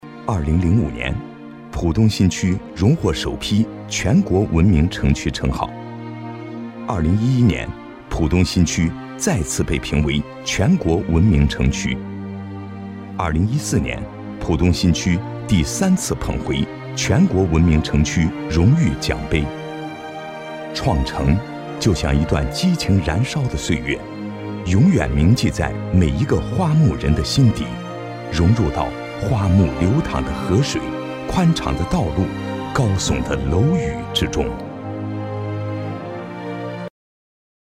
自然诉说 企业专题
声音特点：浑厚低沉、自然、磁性温暖、有韵味。